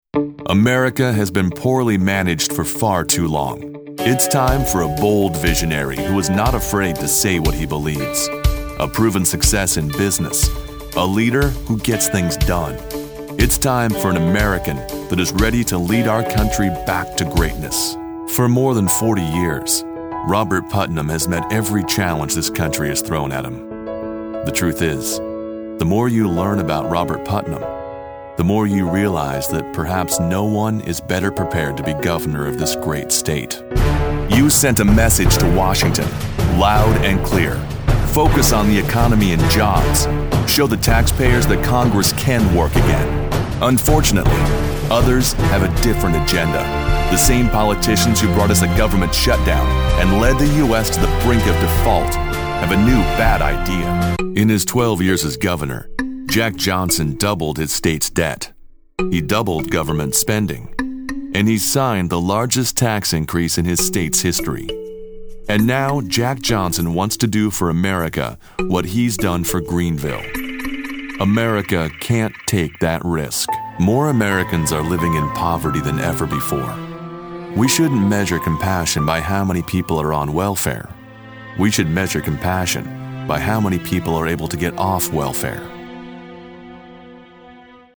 Male Democratic Voices
Voice actors with deep experience, pro home studios and Source Connect.